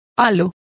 Complete with pronunciation of the translation of halo.